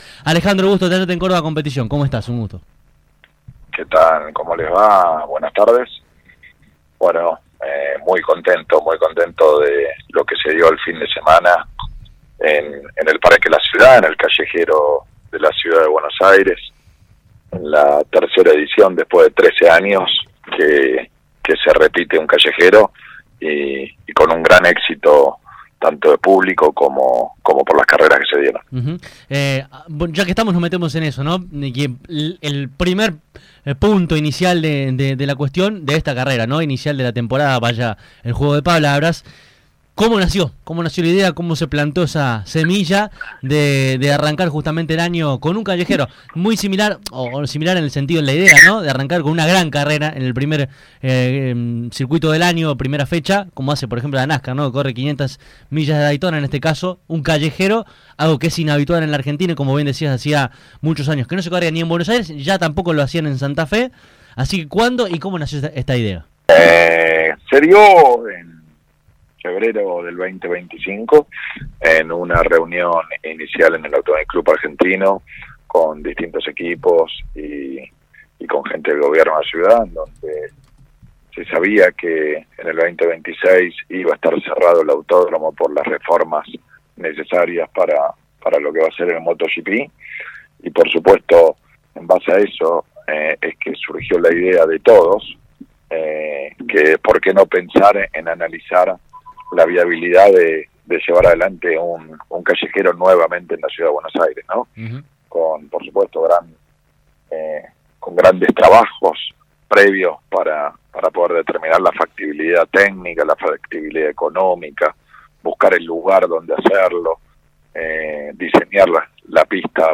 A continuación podrás escuchar esta extensa y muy completa entrevista: